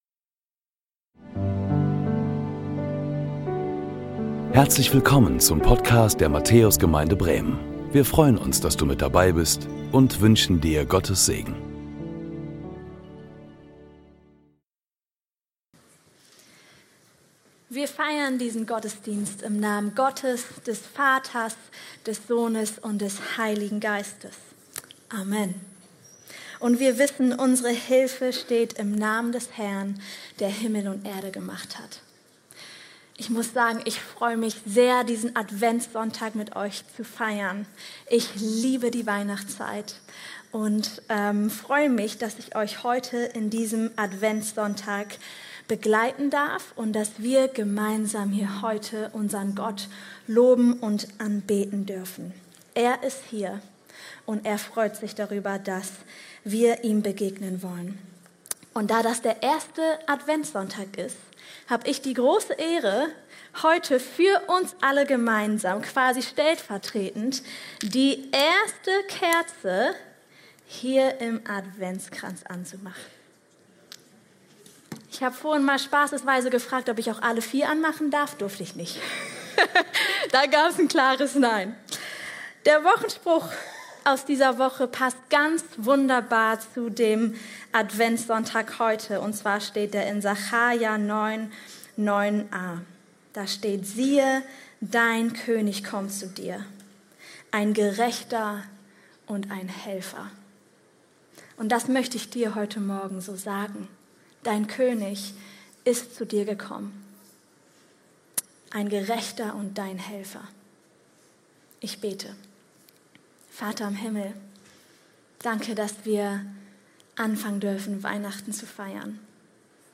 Engel #1 Gottes Rettungsplan ~ Predigten der Matthäus Gemeinde Bremen Podcast